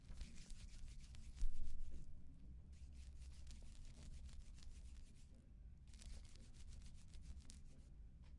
描述：Es el sonido cuando se frota las manos
Tag: 触摸 身体